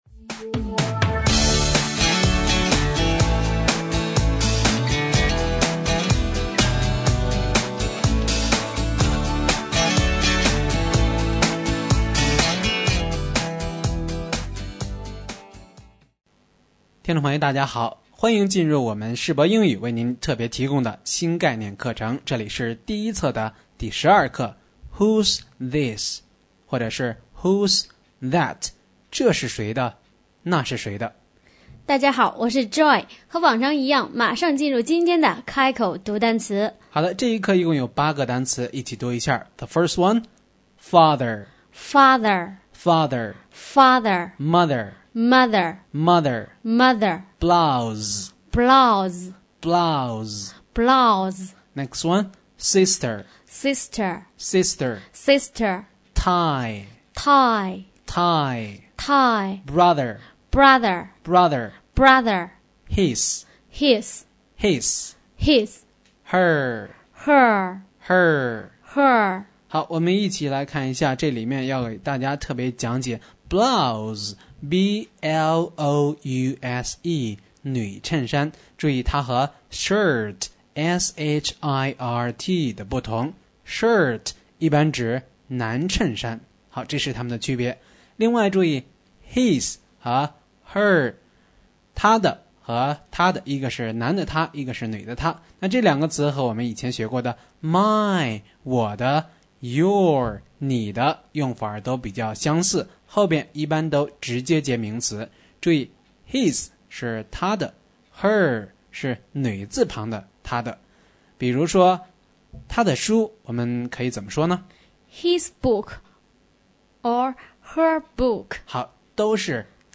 新概念英语第一册第12课【开口读单词】